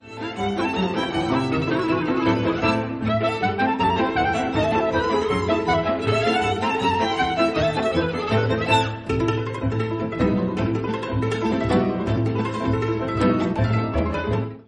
un pianiste et un cymbaliste traditionnel
répertoire traditionnel klezmer et tzigane
celui du "classique world".